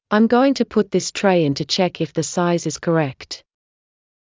ｱｲﾑ ｺﾞｰｲﾝｸﾞ ﾄｩ ﾌﾟｯ ﾃﾞｨｽ ﾄﾚｲ ｲﾝ ﾄｩ ﾁｪｯｸ ｲﾌ ｻﾞ ｻｲｽﾞ ｲｽﾞ ｺﾚｸﾄ